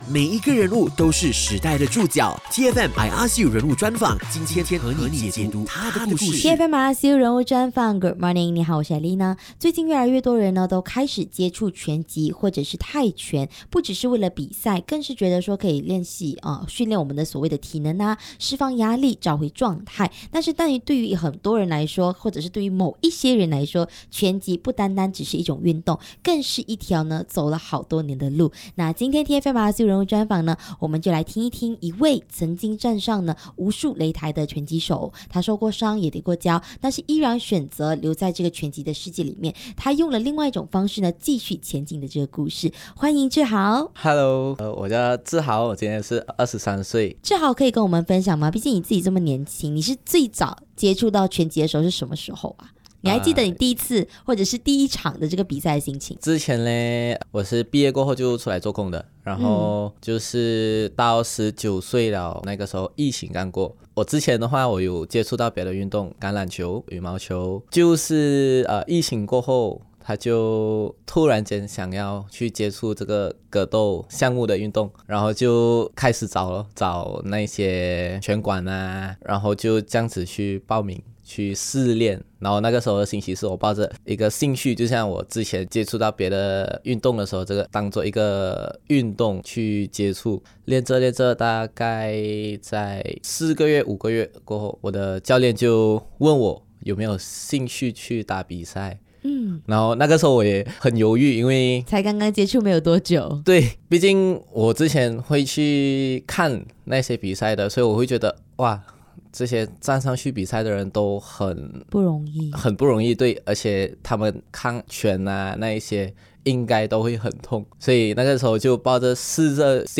人物专访 综合格斗运动员